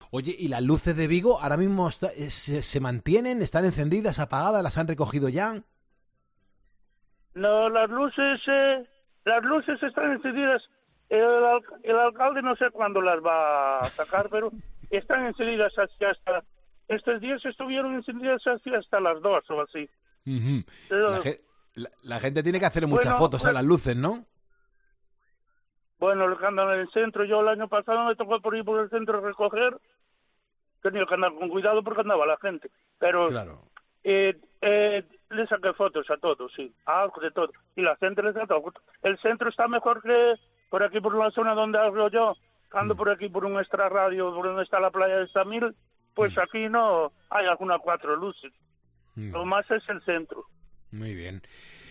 Un barrendero oyente de Poniendo las Calles explicó lo que sucede en los días siguientes.